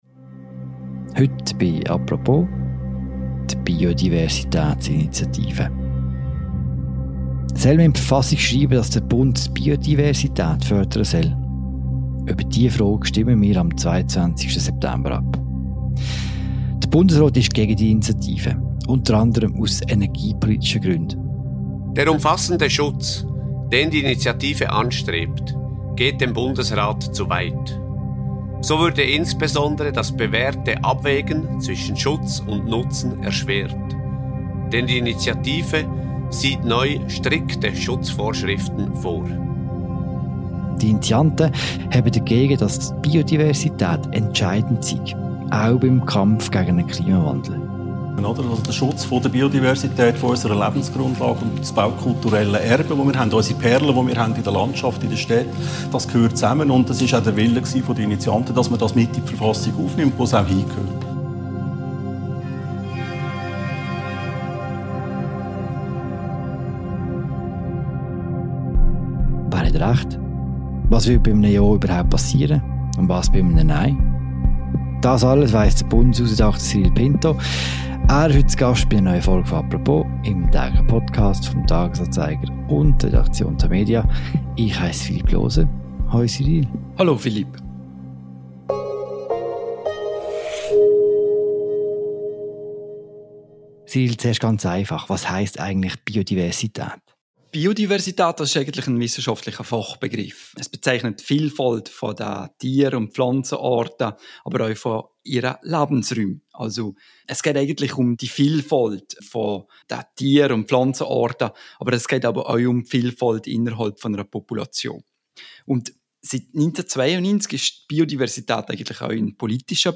Er ist zu Gast in einer neuen Folge von «Apropos», dem täglichen Podcast des Tages-Anzeigers und der Redaktion Tamedia.